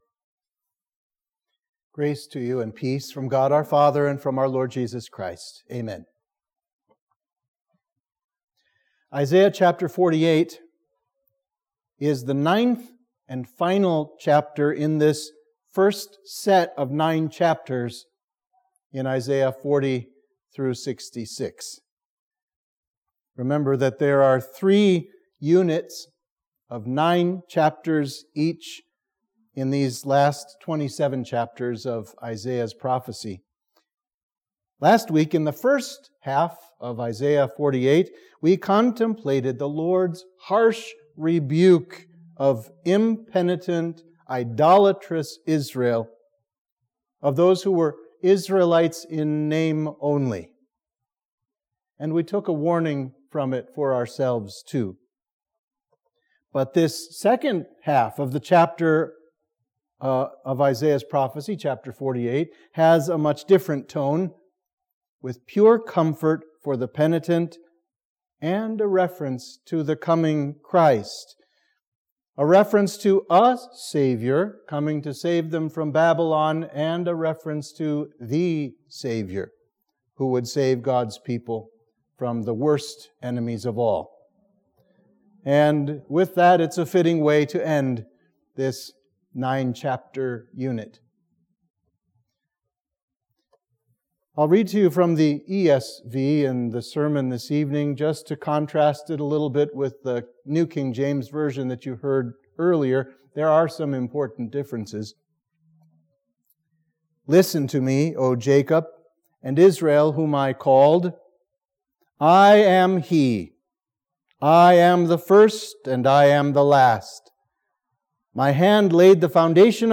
Sermon for Midweek of Trinity 1